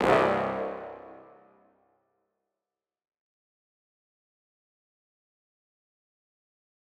MB Hit (3).wav